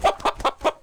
combat / creatures / chicken / he / attack1.wav